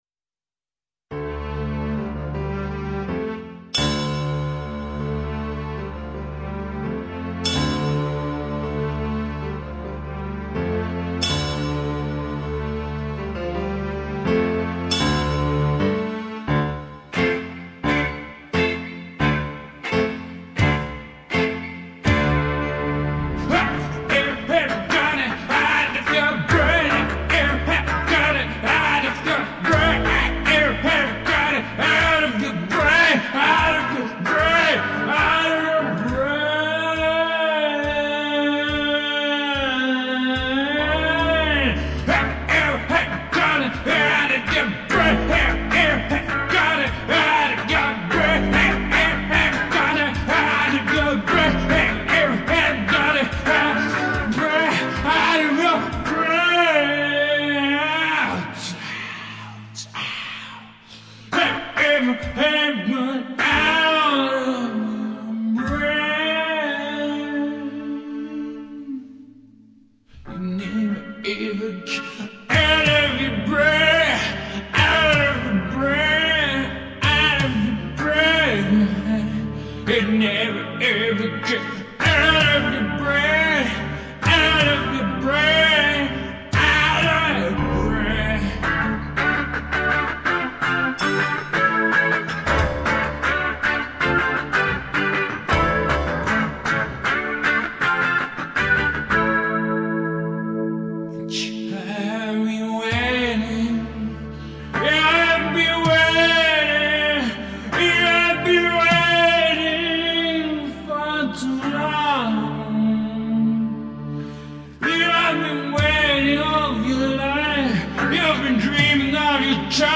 Rock/Blues MP3